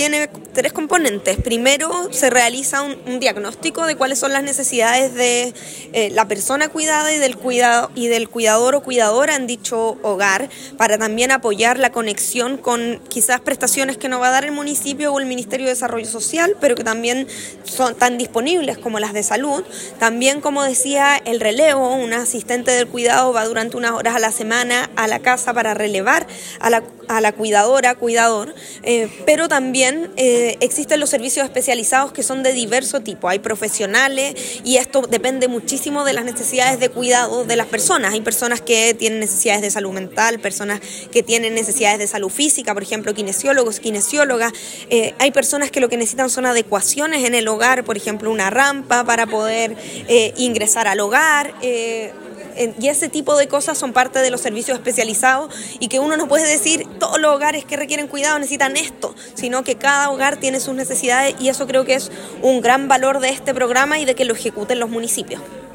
Del mismo modo, la jefa de cartera de Desarrollo Social, explicó que este programa permite el trabajo mancomunado entre distintas instituciones que no solo permiten entregar apoyo a los cuidadores, sino que entregan mejoras en su calidad de vida.